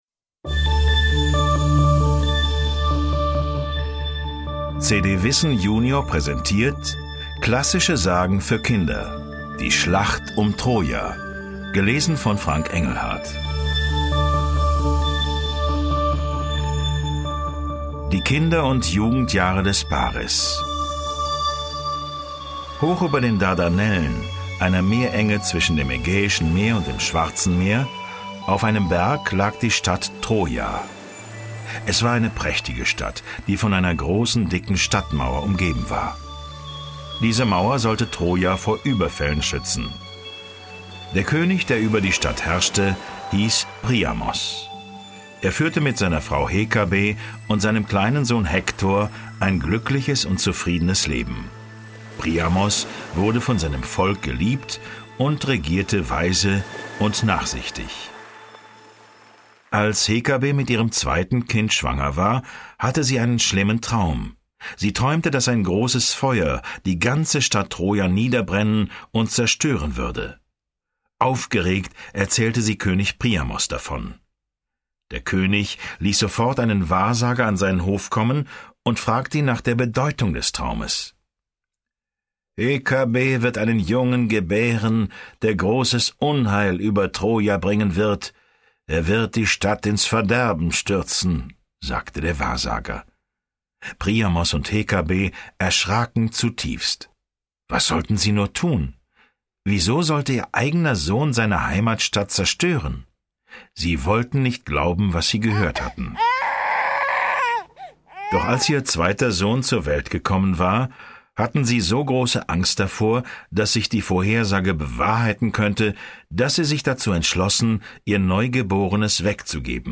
Kinder- / Jugendbuch Vorlesebücher / Märchen